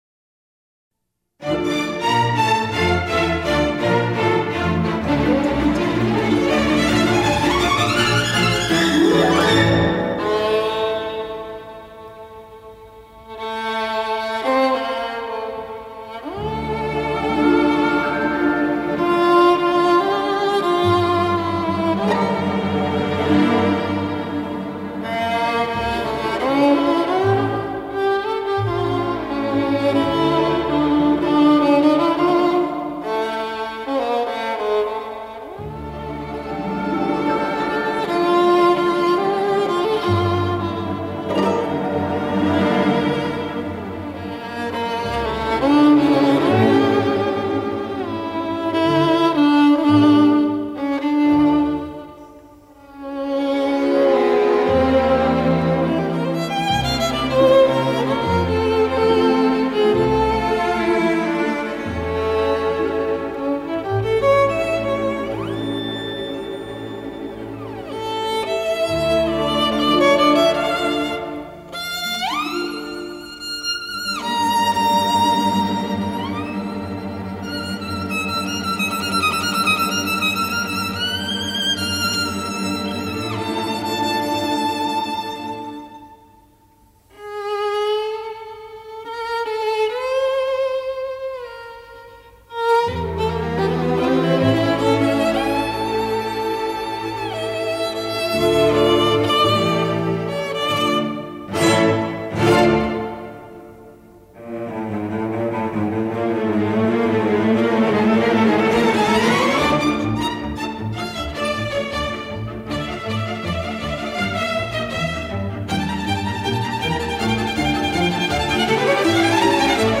蒙蒂 -《查尔达什舞曲》 乐曲一开始先呈示出充满激情的引子，然后奏出深沉而略含忧愁的主题，并由这一主题组成了查尔达什舞曲特有的"拉绍"段落。在这一小调色彩的抒情旋律发展之后，又出现另一支流畅而华丽的小调旋律。这两支旋律都具有鲜明的匈牙利及吉普赛音乐的特点。
这段舞曲音乐越来越热烈，由十六分音符组成的乐句频繁出现，使乐曲趋向高潮。接着，音乐突然平静下来，缓缓奏出柔和而委婉的歌唱性主题旋律。这一纯朴甜美的旋律经过小提琴泛音再现，形成一种幽谷回声的动人效果。乐曲再现十六分音符组成的乐句，并用切分节奏的处理，将乐曲推向热烈的高潮后结束全曲。